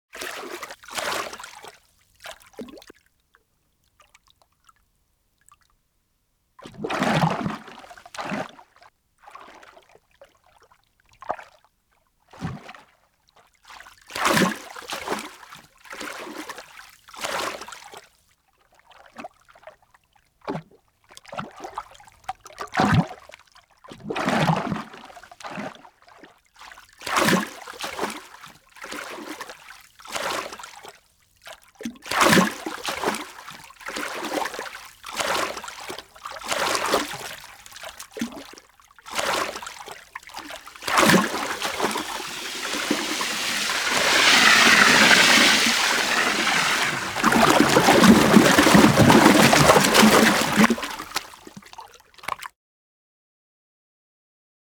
animal